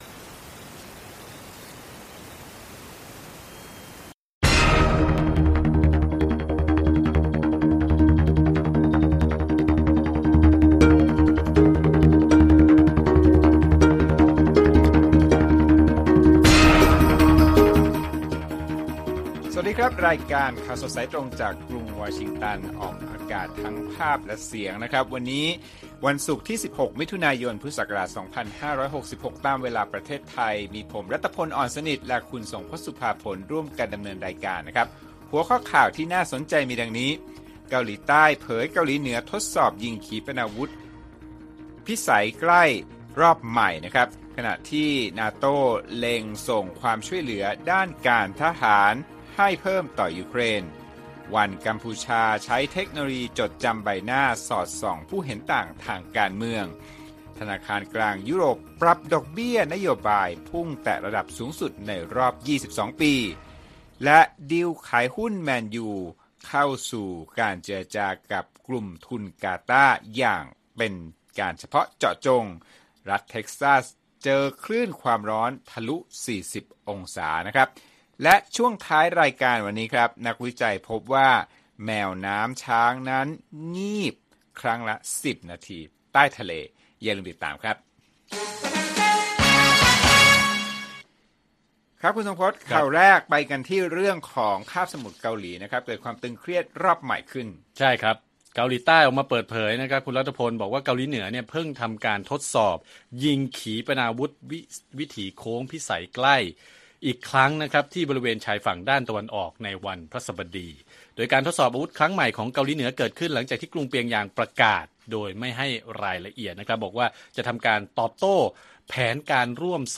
ข่าวสดสายตรงจากวีโอเอไทย 6:30 – 7:00 น. วันที่ 16 มิ.ย. 2566